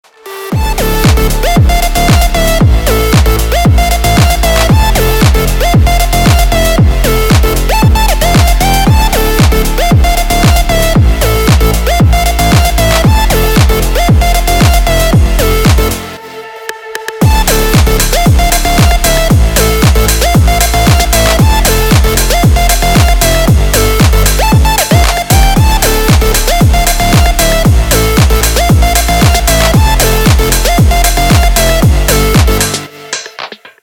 Громкие звонки, звучные рингтоны